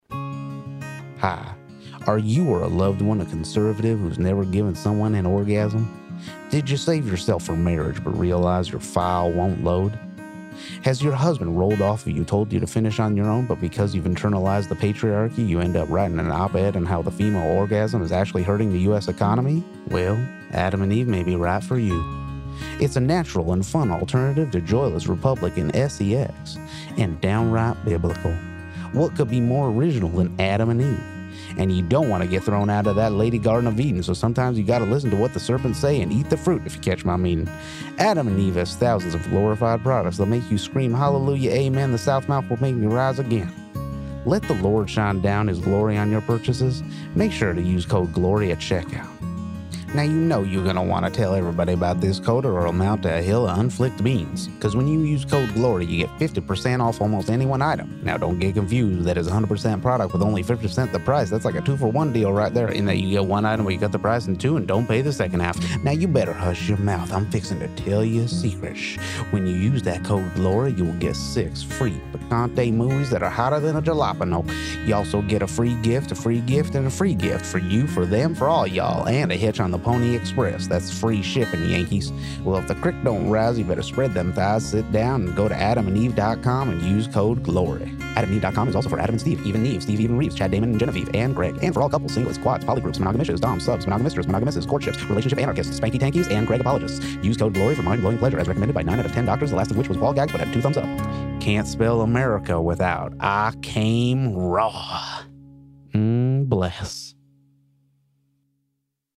Conservative Country Ad.mp3
Texan here, and you shore did do us some justice with that suthern accent o’ yore’s!